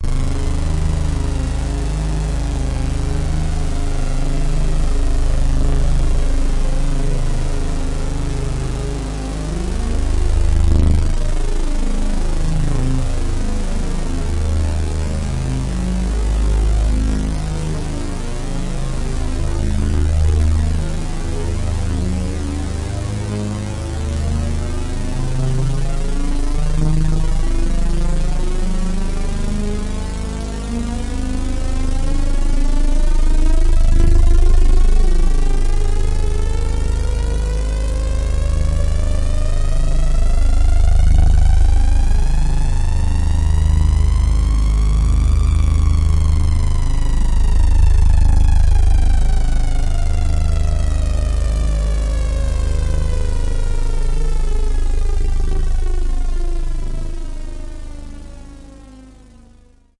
Q multi 004: saw LF Oed filter sweep " Q Saw LF Oed filter sweep C2
描述：这是一个来自我的Q Rack硬件合成器的锯齿波声音，上面强加了一个低频滤波器调制。由于LFO的频率相当低，我不得不创建长的采样，以获得比一个完整的LFO周期更多一点。这个声音在文件名称中的键上。它是"Q multi 004: saw LFOed filter sweep" 样品包的一部分。用Waldorf Q Rack合成器制作（使用所有3个振荡器和所有效果器的旁路），通过我的Spirit 328数字控制台在Cubase 4中以32位波形文件进行数字录制。录音后只进行了归一化、淡化、抖动和转换为flac格式。
标签： 电子 过滤 多样品 合成器 华尔
声道立体声